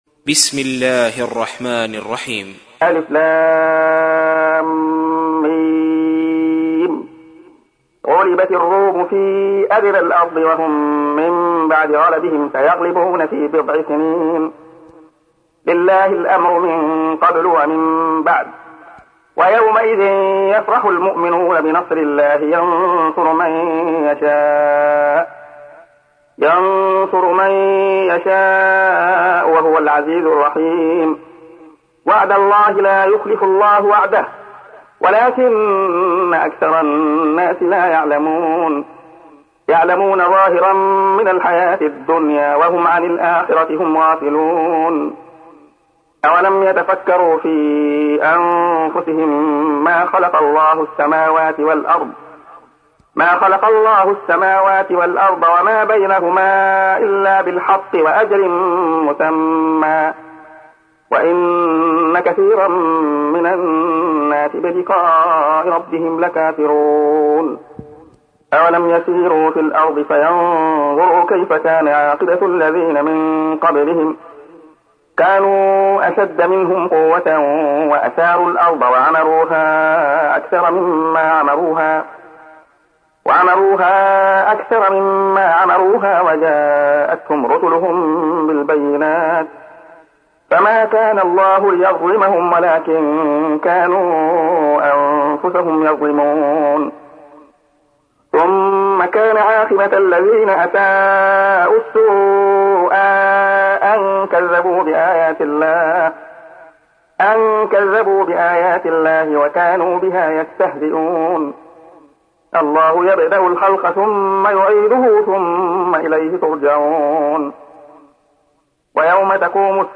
تحميل : 30. سورة الروم / القارئ عبد الله خياط / القرآن الكريم / موقع يا حسين